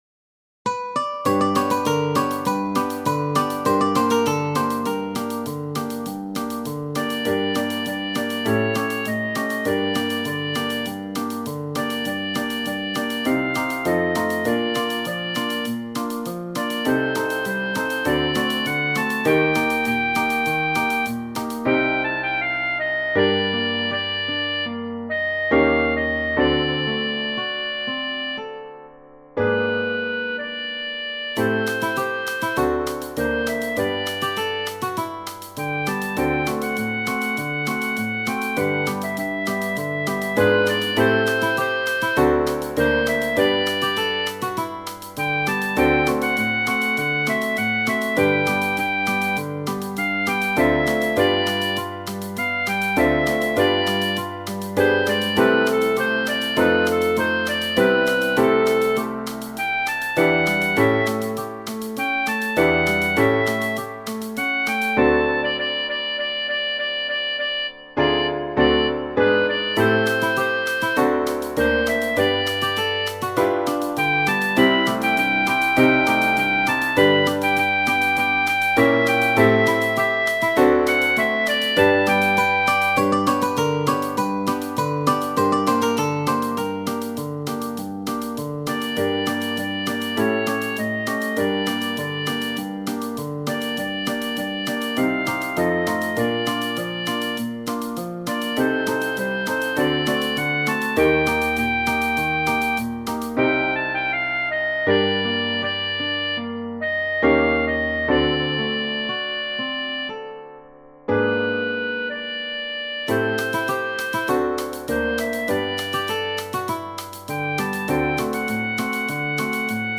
Genere: Napoletane